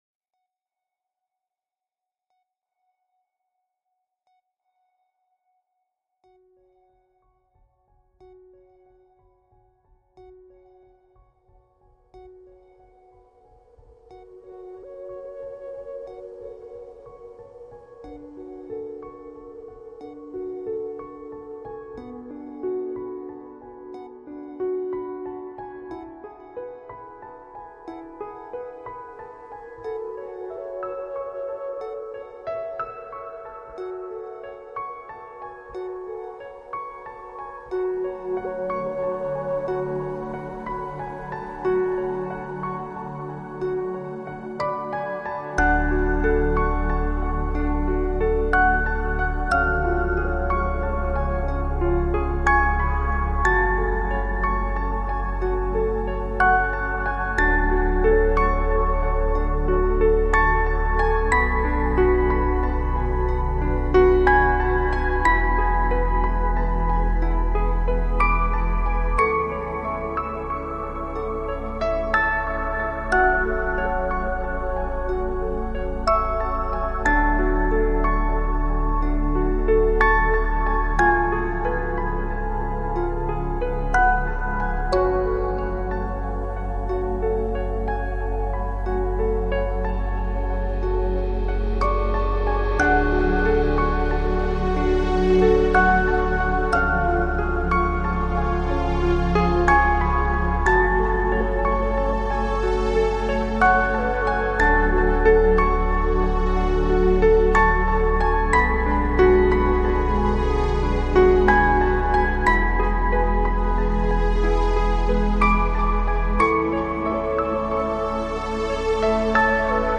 Relax, Chillout, Downtempo Год издания